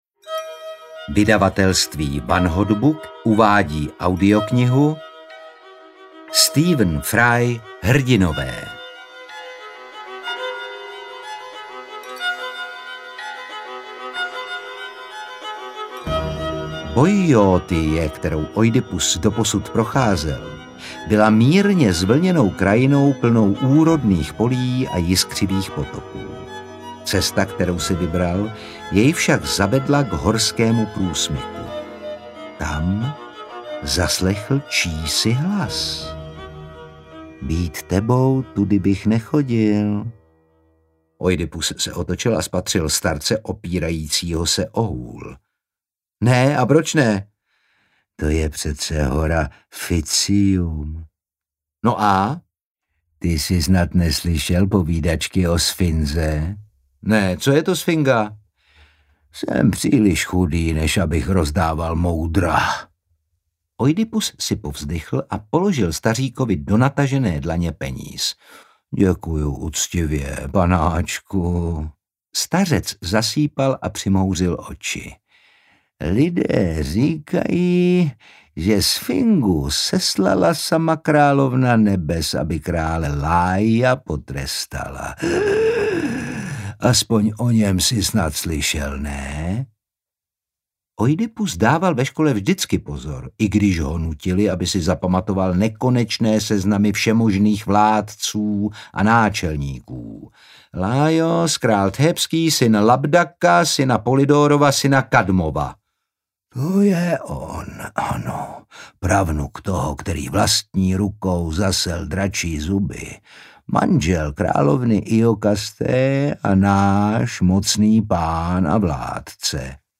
Hrdinové audiokniha
Ukázka z knihy